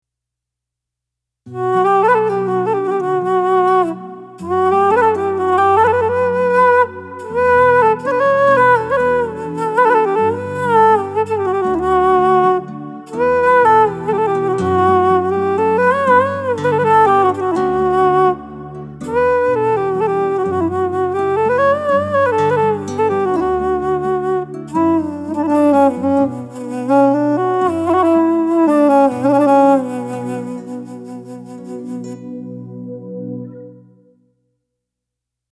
Ethnic Flute demo